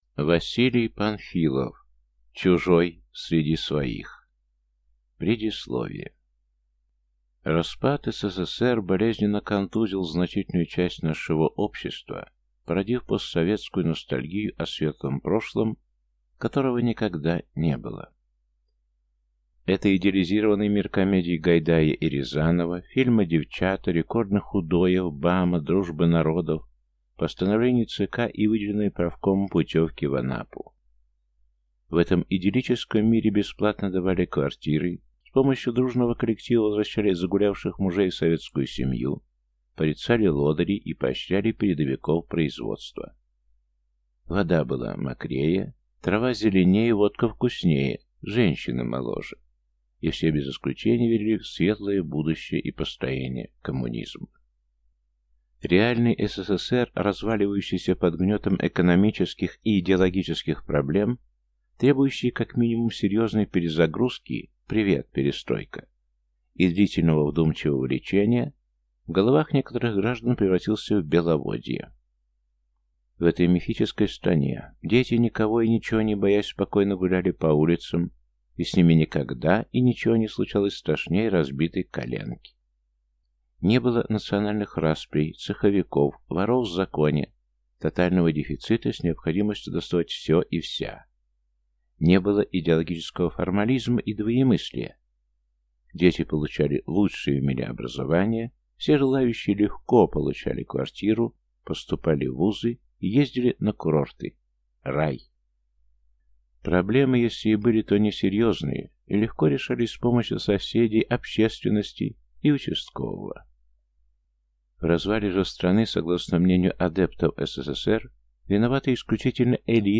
Аудиокнига Чужой среди своих | Библиотека аудиокниг